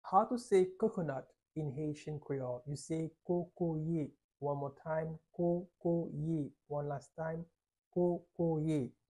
How to say Coconut in Haitian Creole -Kokoye pronunciation by a native Haitian Teacher
“Kokoye” Pronunciation in Haitian Creole by a native Haitian can be heard in the audio here or in the video below:
How-to-say-Coconut-in-Haitian-Creole-Kokoye-pronunciation-by-a-native-Haitian-Teacher.mp3